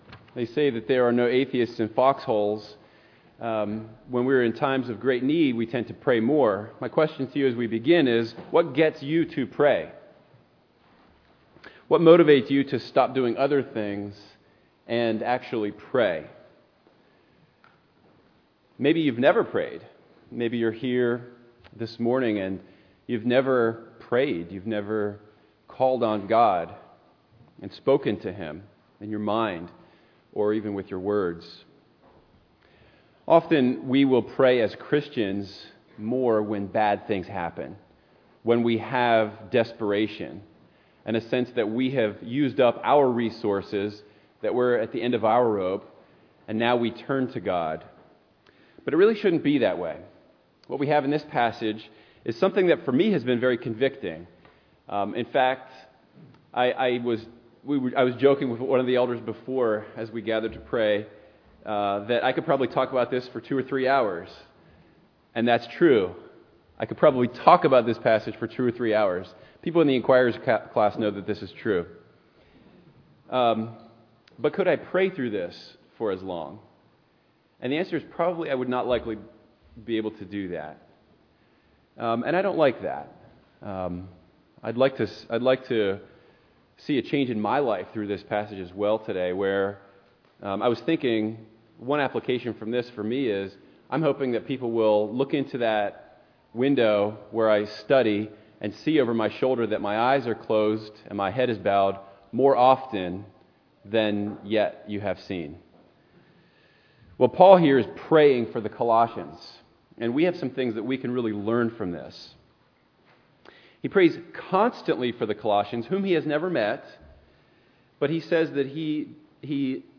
2022 Sermons